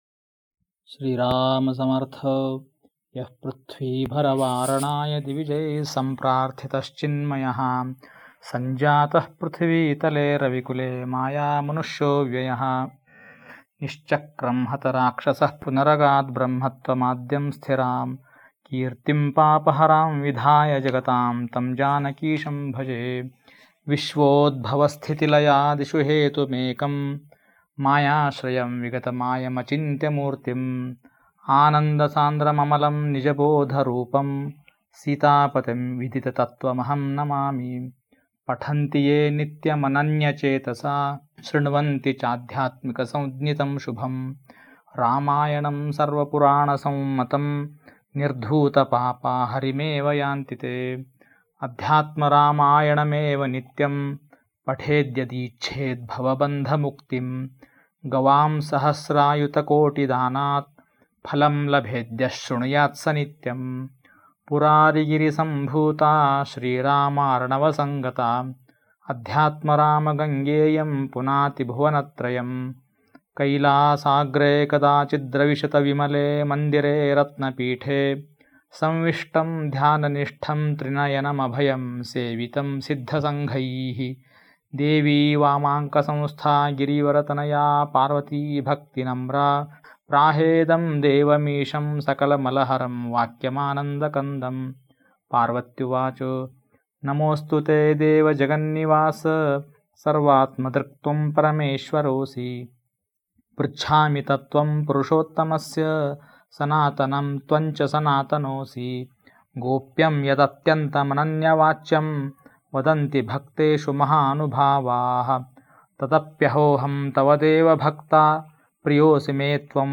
श्री सद्गुरूलीलामृत पठण